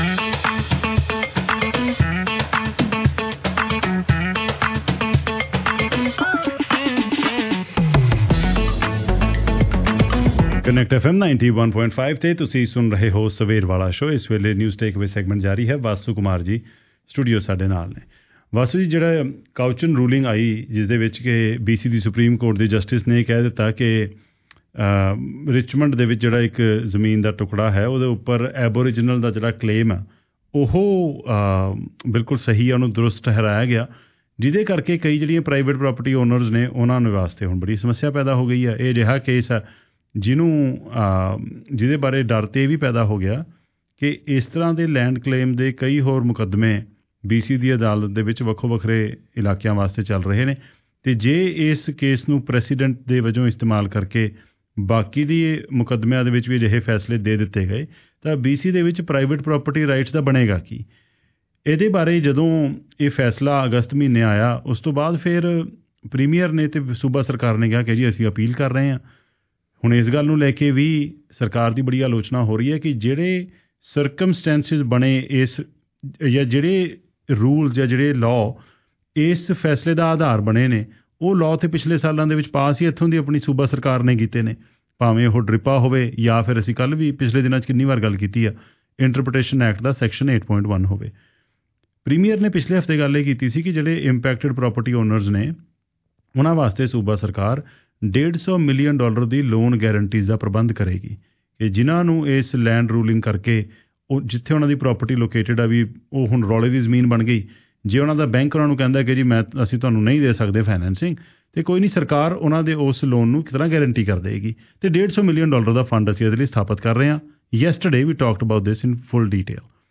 Tune in for expert analysis and community impact discussions.